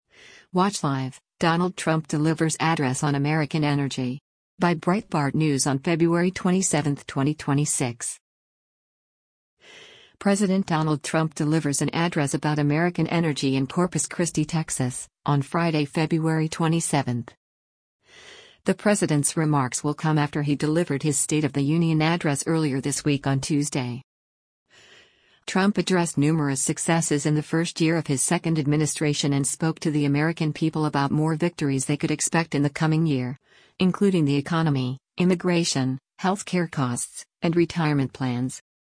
President Donald Trump delivers an address about American energy in Corpus Christi, Texas, on Friday, February 27.